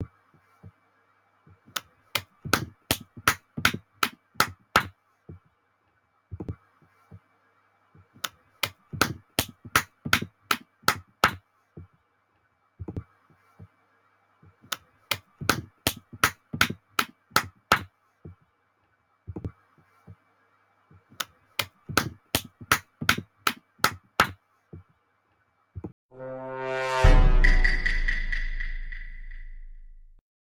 GET CLAPPED BY THE SIGMA - Botão de Efeito Sonoro